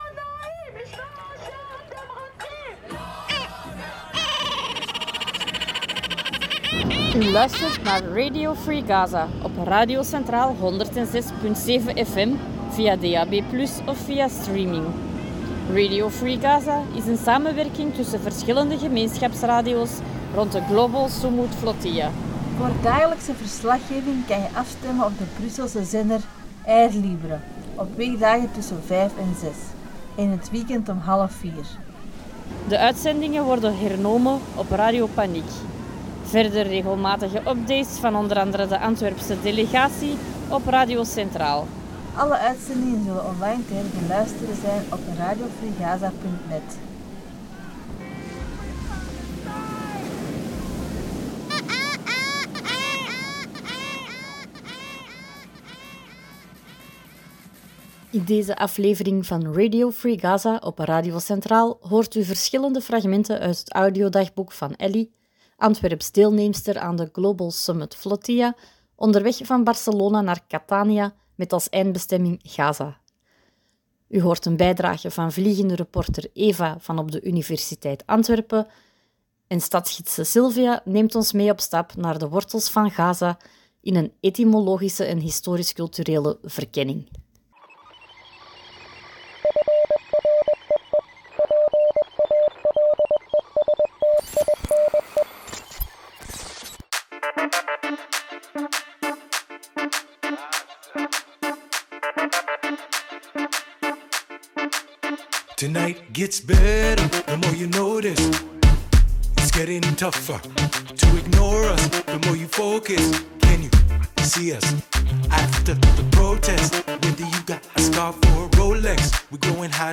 Onderweg van Barcelona naar Catania met als eindbestemming Gaza. In deze aflevering van Radio Free Gaza op Radio Centraal hoort u verschillende fragmenten uit het audiodagboek